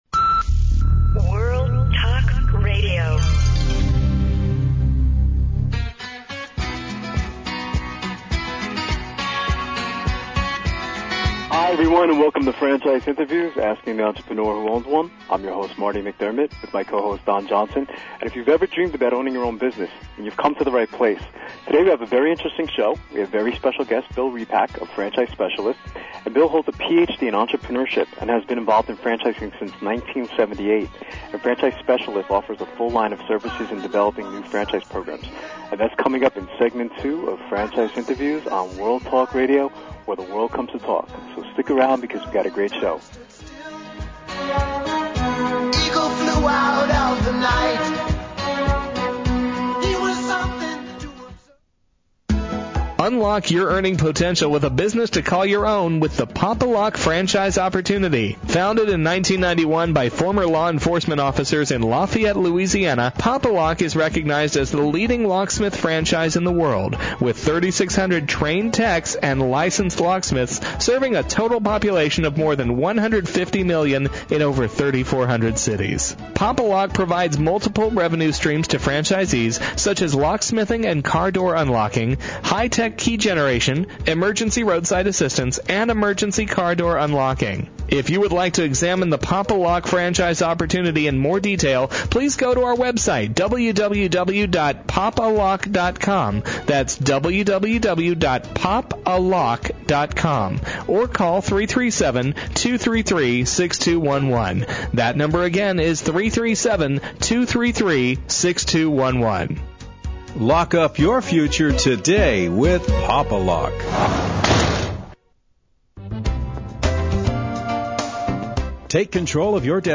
Franchise Interviews meets with Franchise Specialist Inc.